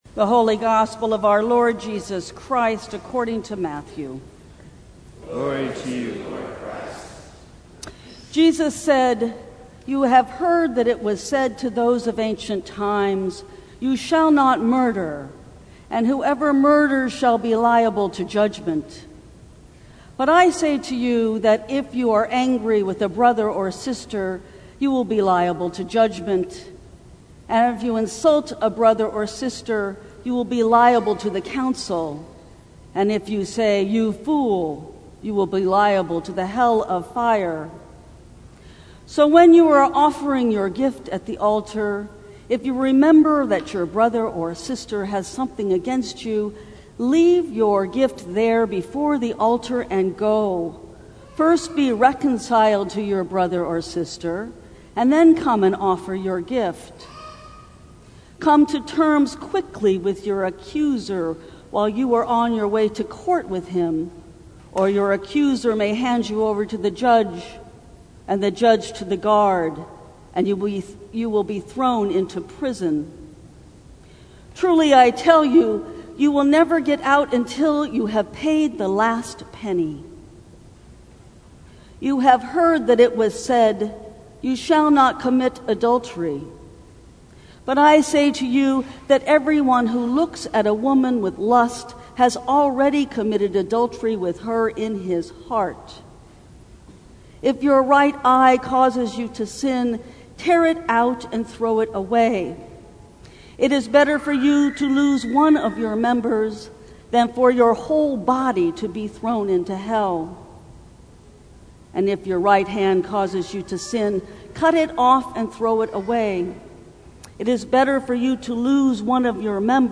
Sermons from St. Cross Episcopal Church Live On Feb 16 2020 | 00:14:41 1x 00:00 / 00:14:41 Subscribe Share Apple Podcasts Spotify Overcast RSS Feed Share Link Embed